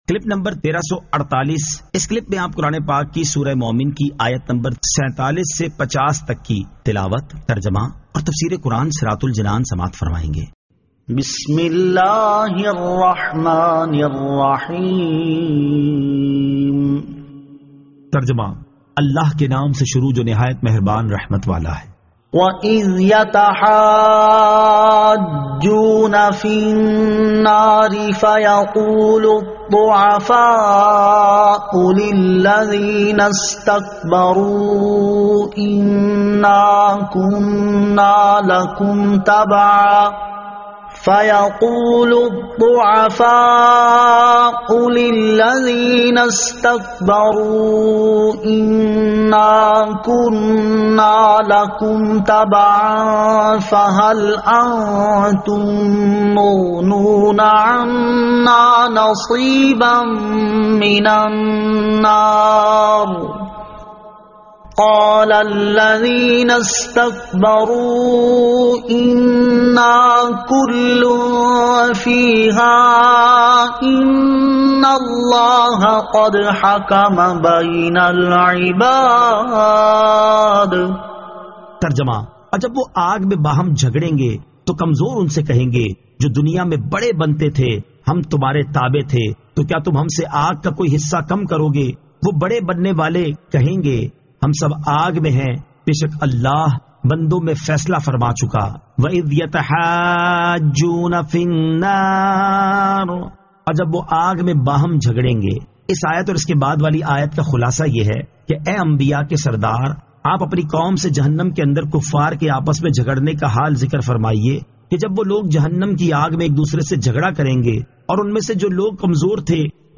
Surah Al-Mu'min 47 To 50 Tilawat , Tarjama , Tafseer